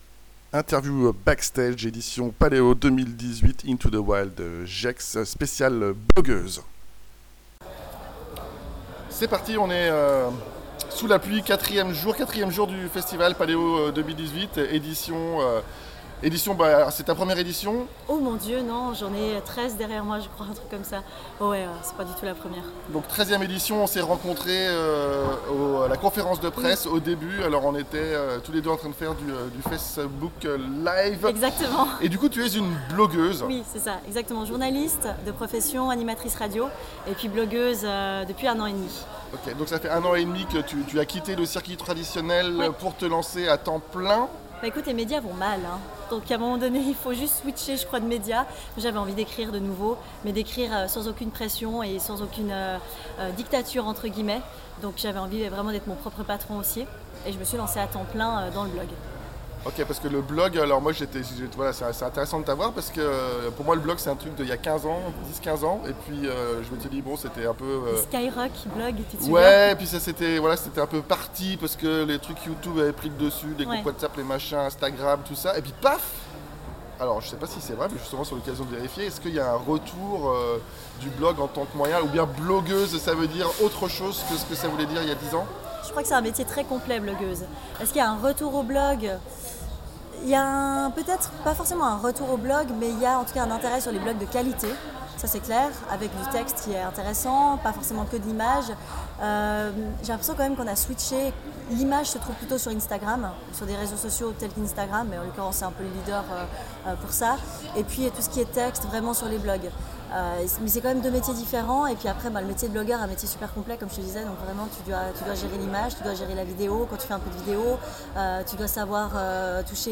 Interview backstage Paléo 2018